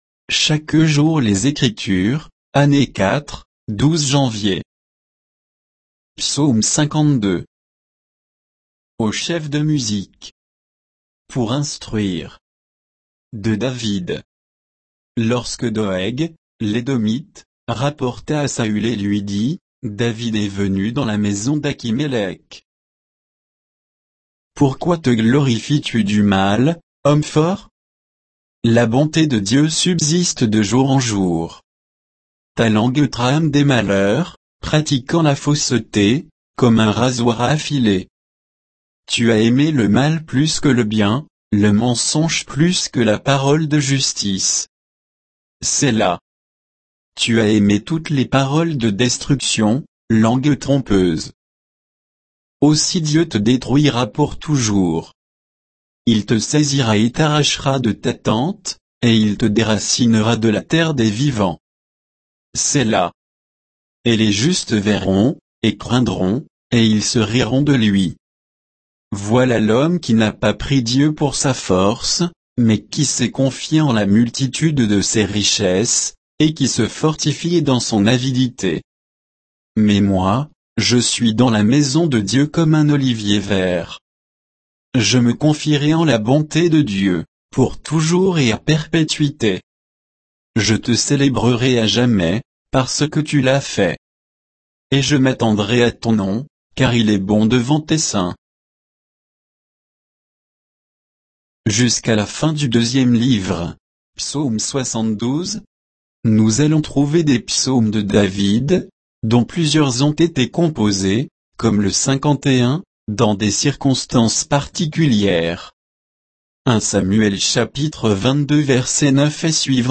Méditation quoditienne de Chaque jour les Écritures sur Psaume 52